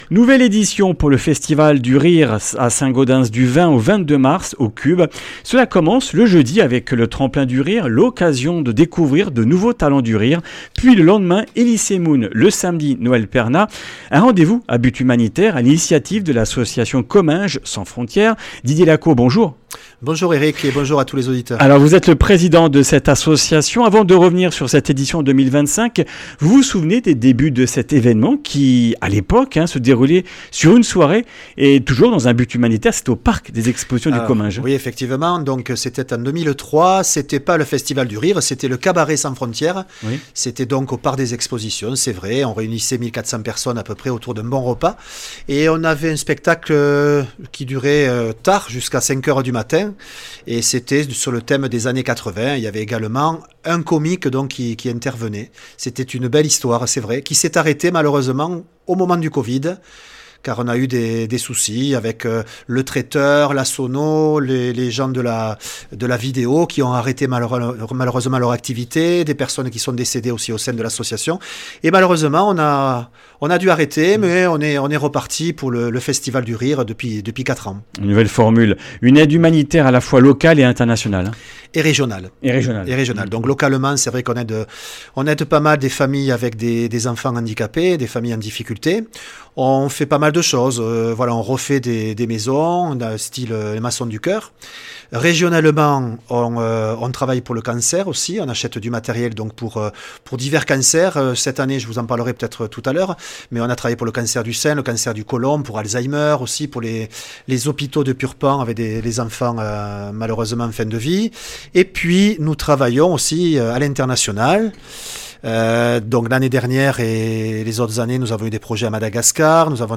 Comminges Interviews du 25 mars